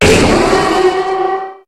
Cri de Méga-Démolosse dans Pokémon HOME.
Cri_0229_Méga_HOME.ogg